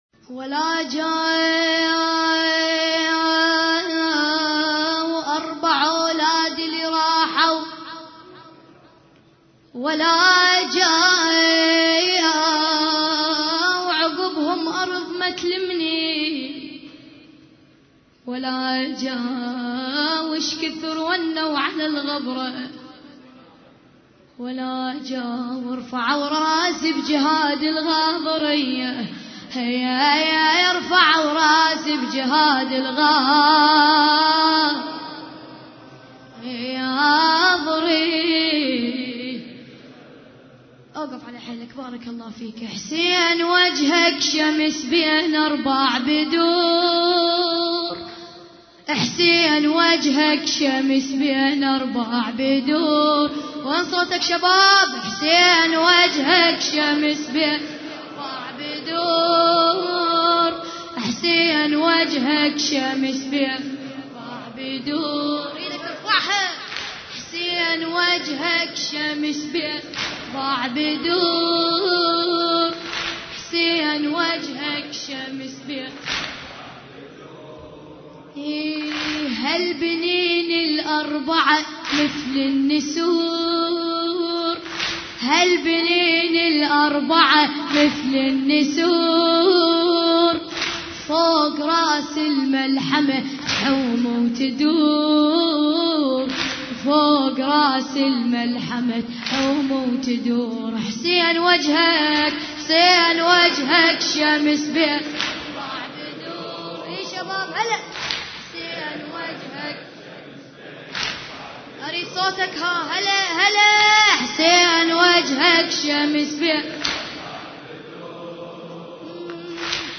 عزاء ليلة 4 محرم 1433 هجري